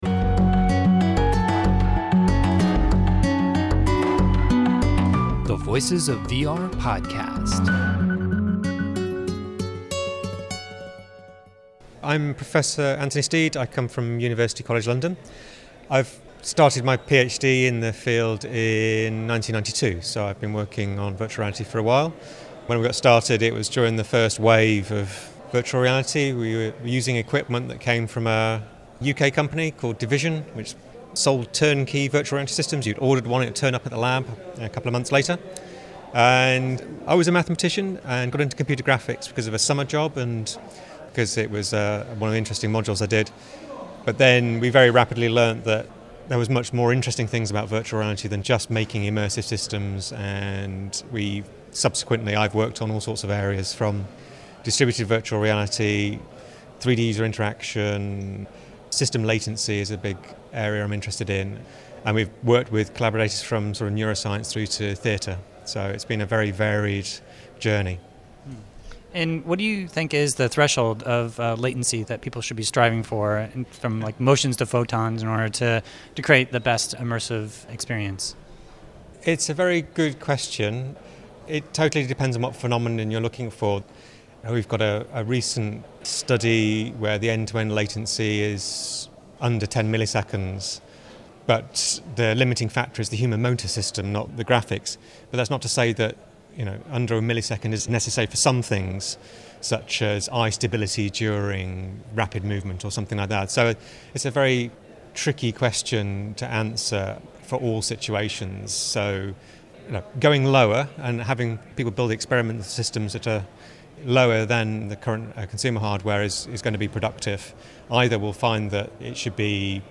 Here’s some of the topics that we discussed at the IEEE VR conference: